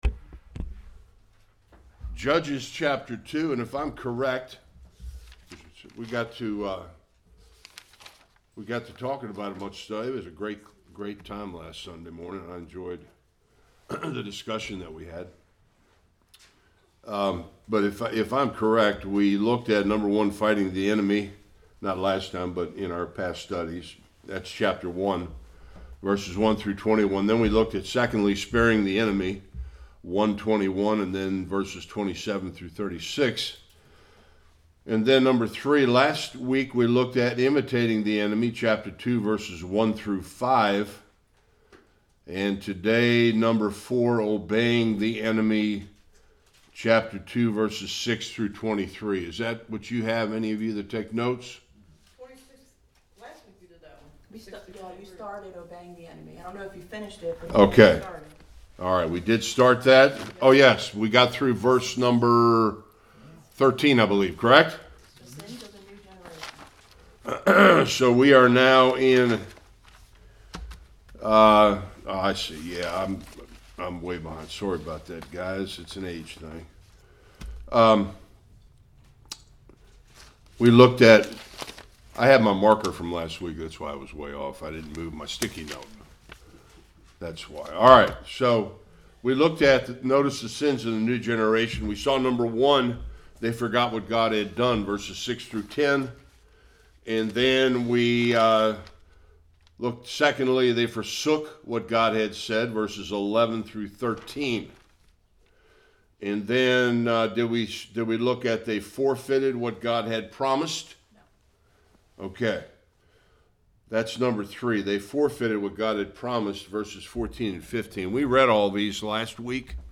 1-7 Service Type: Sunday School The Lord sought to test and train Israel and they failed miserably.